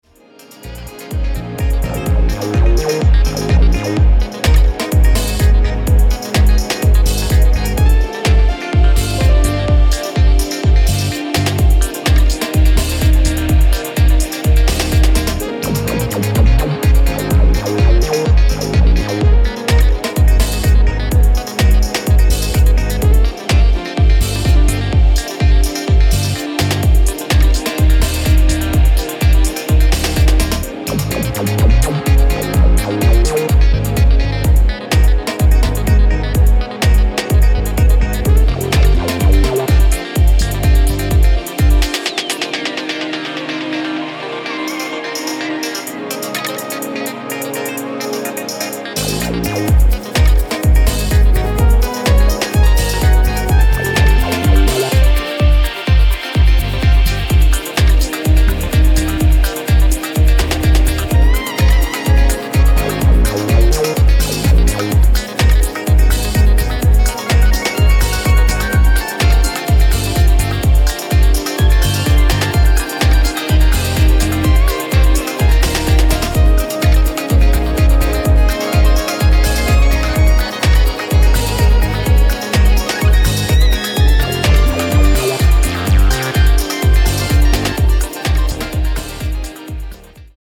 ここでは、自身の手による鍵盤やフルートなどのジャジーな演奏を用いながら温かくしなやかなフュージョン・ハウスを展開。